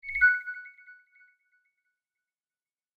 Alert 3.m4a